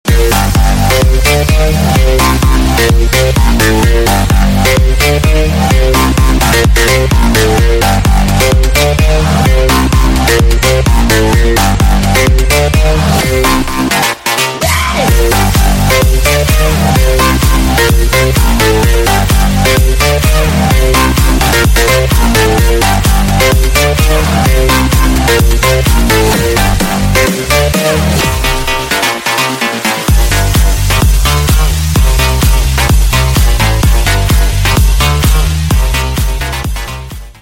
Клубные Рингтоны » # Рингтоны Без Слов
Танцевальные Рингтоны » # Рингтоны Электроника